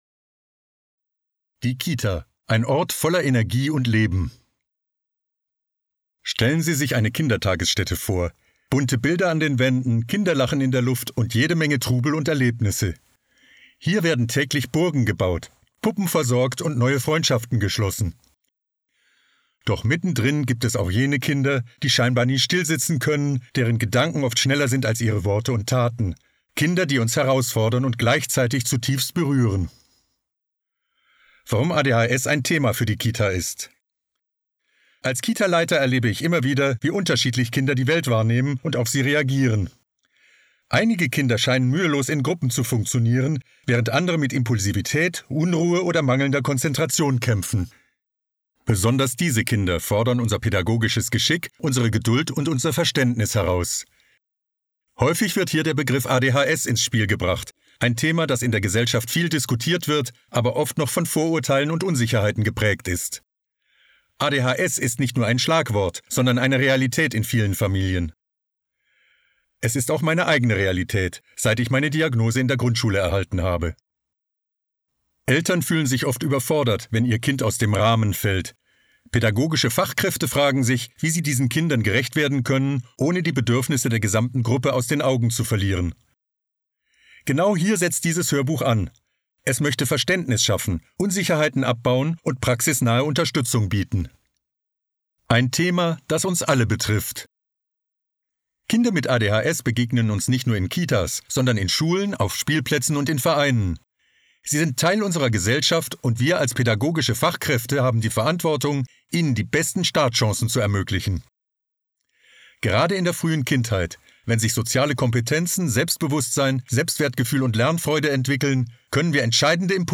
Hörbuch